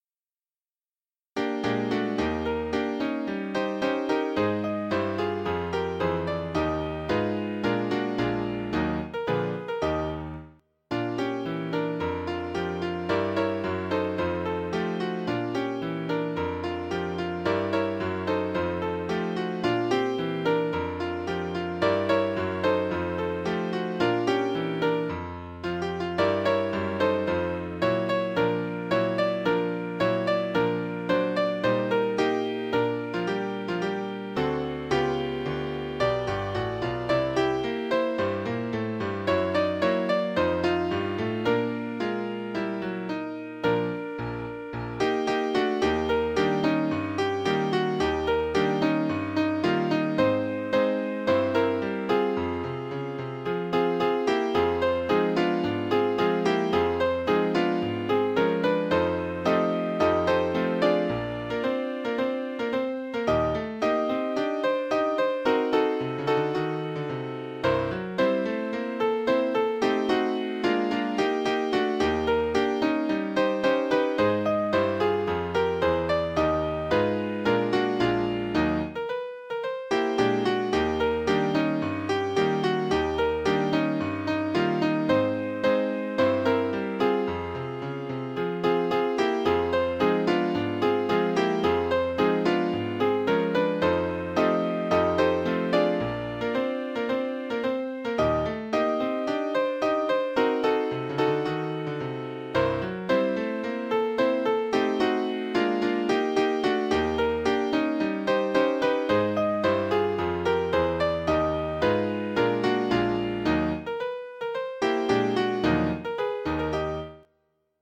Sheet music from the Broadway musical A Lonely Romeo